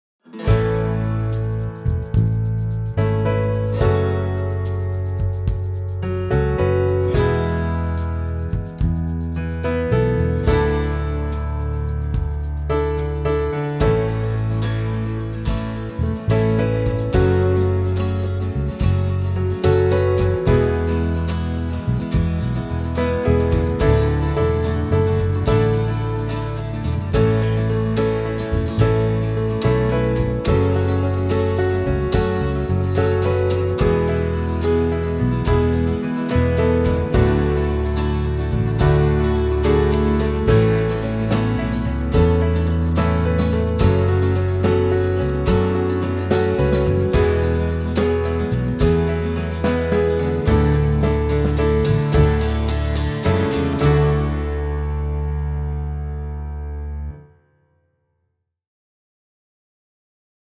Genre: Documentary Underscore.